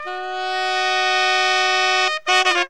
HORN RIFF 22.wav